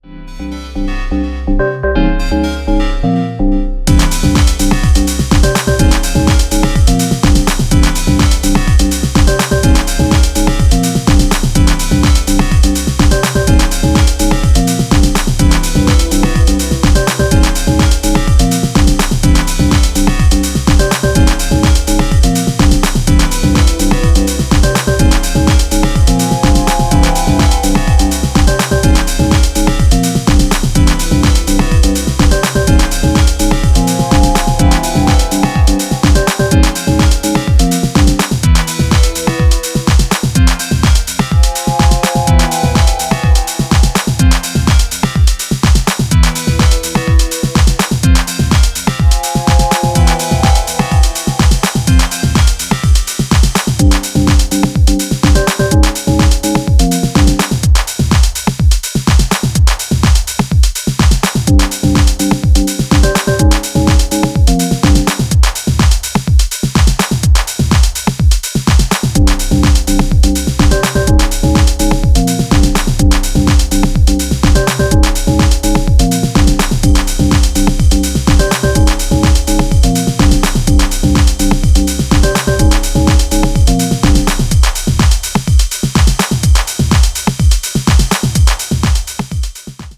透明感とダスティなムードが混在するジャッキン・ハウス